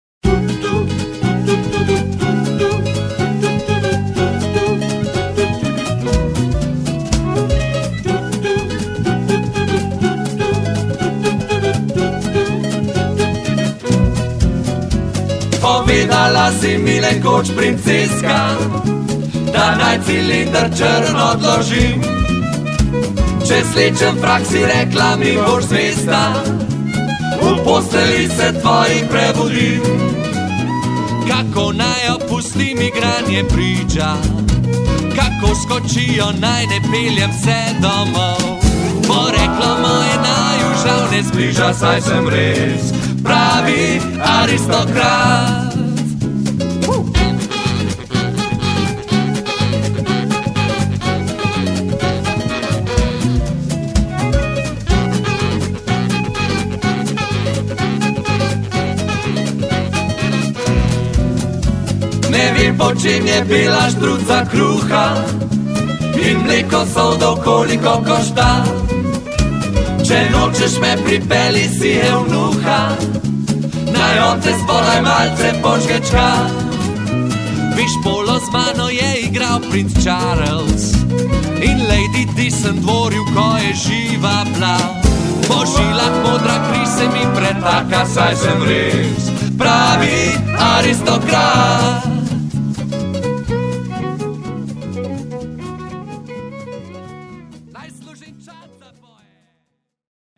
akustična kitara
violina
bas kitara
bobni
Posneto: januar-april 2004, Kranj, studio Poet.